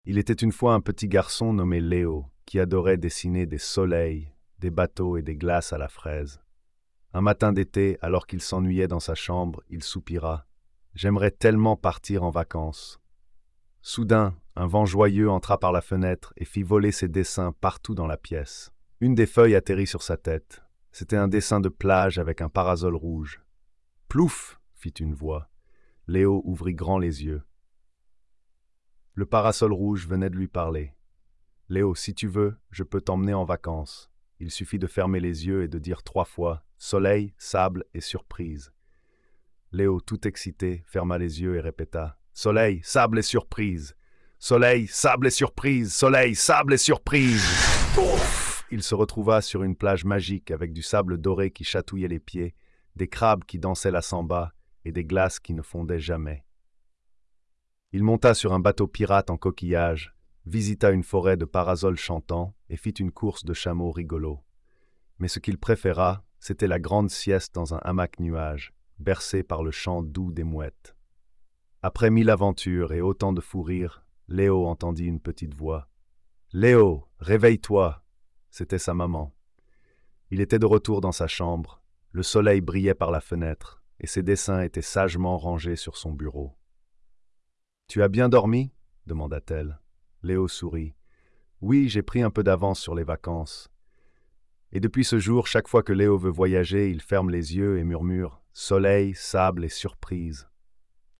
Léo et les Vacances Magiques - Conte de fée
🎧 Lecture audio générée par IA